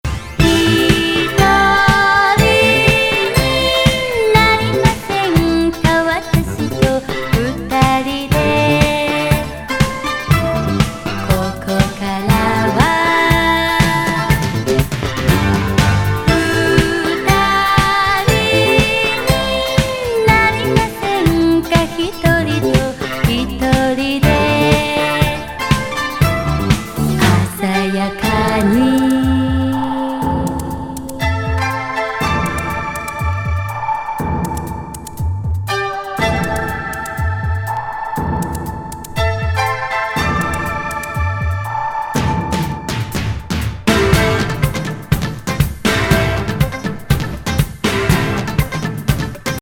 ジャングル・ビートにチャイニーズ・アレンジも入ったグルーヴィー歌謡!